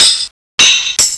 PERC LOOP4-R.wav